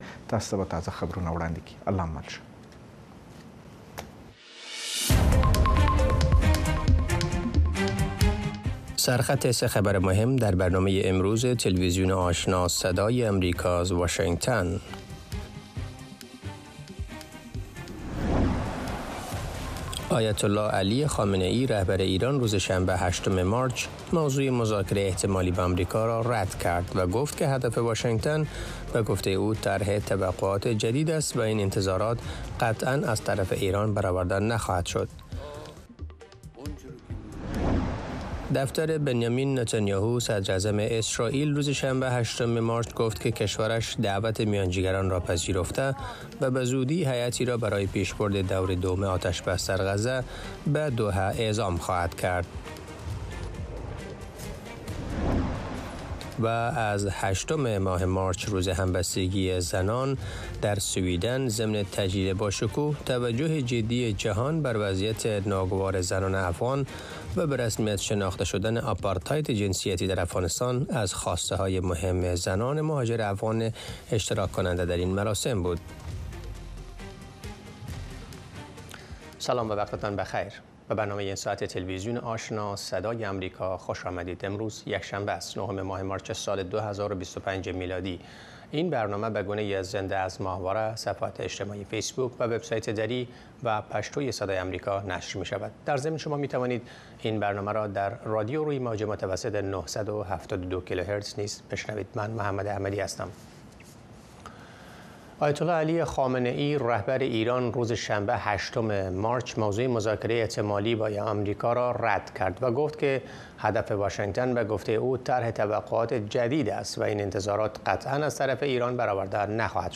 تازه‌ترین خبرهای افغانستان، منطقه و جهان، گزارش‌های جالب و معلوماتی از سراسر جهان، مصاحبه‌های مسوولان و صاحب‌نظران، صدای شما و سایر مطالب را در برنامهٔ خبری آشنا از روزهای شنبه تا پنج‌شنبه در رادیو، ماهواره و شبکه های دیجیتلی صدای امریکا دنبال کنید.